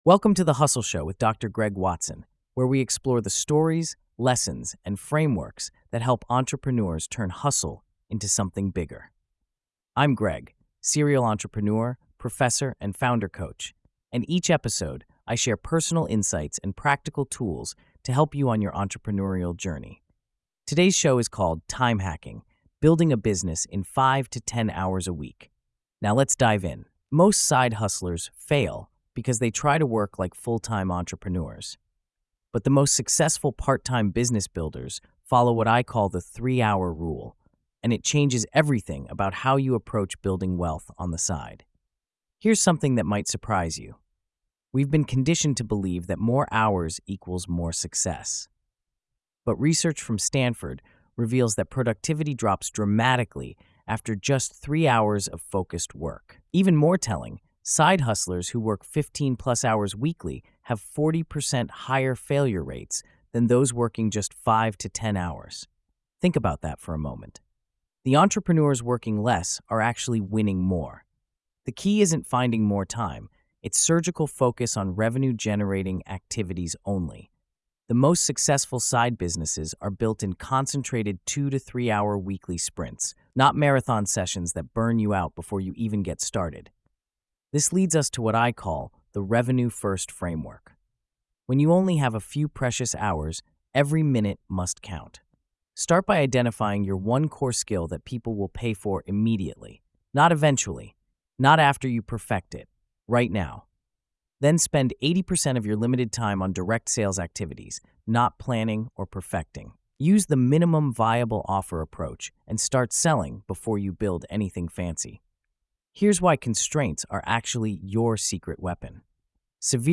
Delivered in a thoughtful, TED Talk-style voice, The Hustle Show gives listeners not just tactics, but the “why” behind successful entrepreneurship.